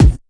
Kick_54.wav